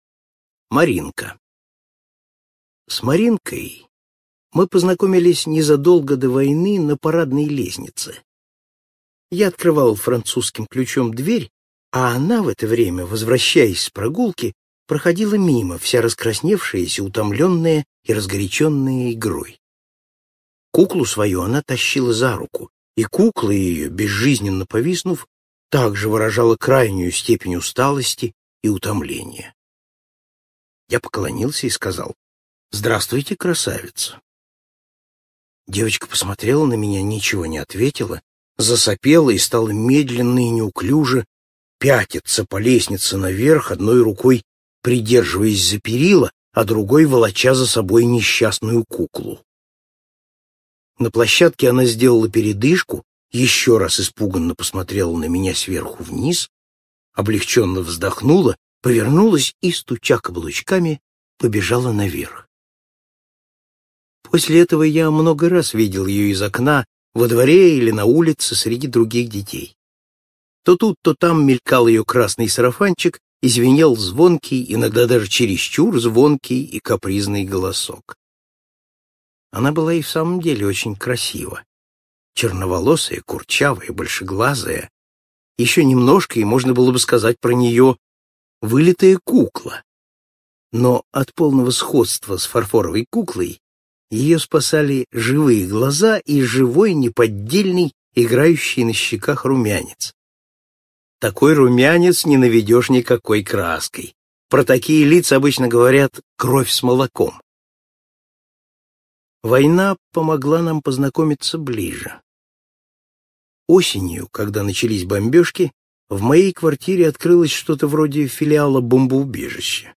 Слушайте Маринка - аудио рассказ Пантелеева Л. Рассказ про шестилетнюю девочку Маринку в военные годы блокадного Ленинграда.